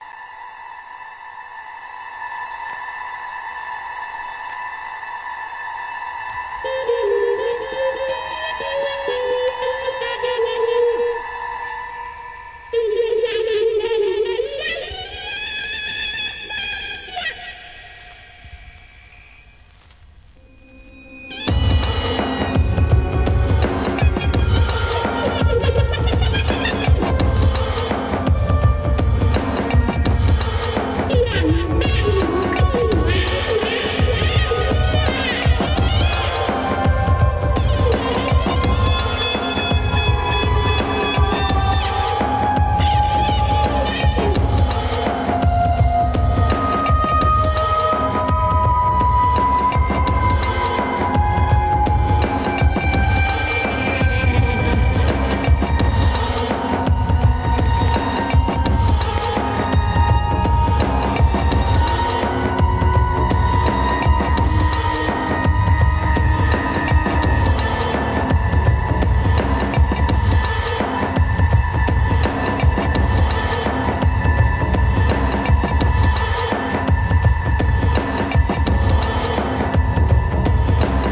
UK TRIP HOP ブレイクビーツ